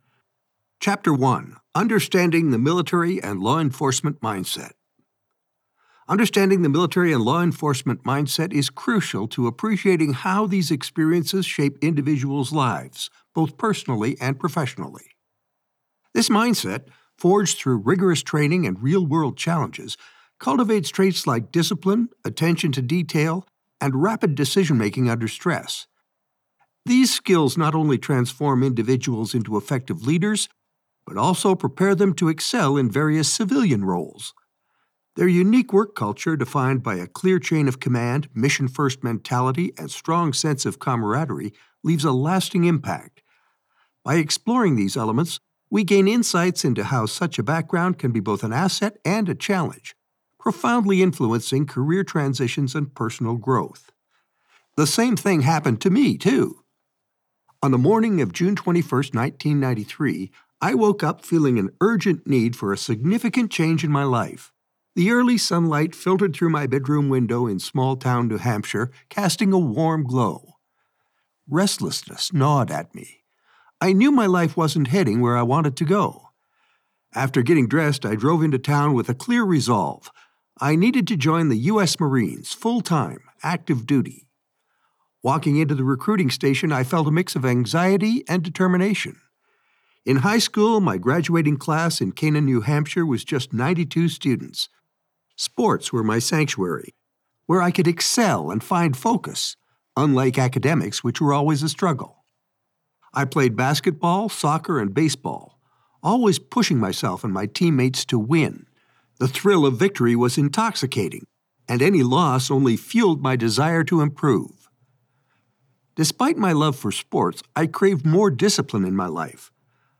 A signature voice - mature, warm, and engaging
BATTLEFIELDS TO BOARDROOMS - audiobook - business
Middle Aged